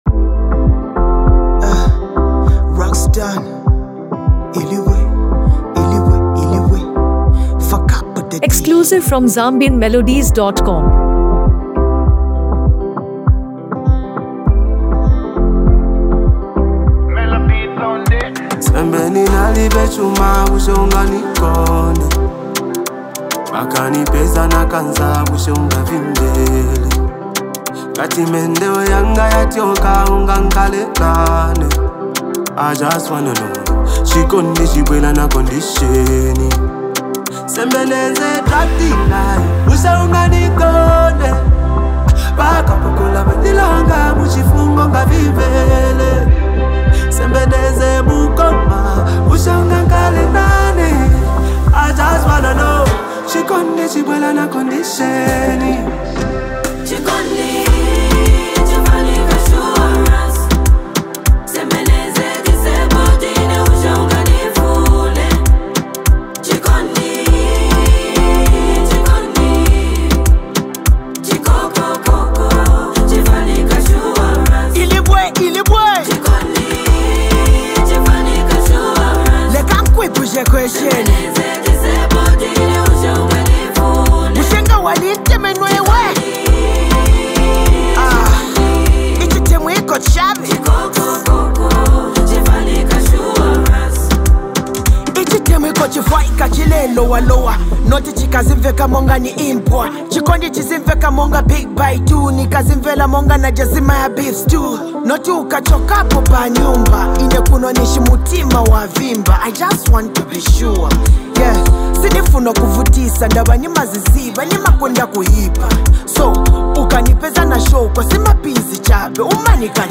female rapper
a polished blend of rap, melody, and modern Zed rhythms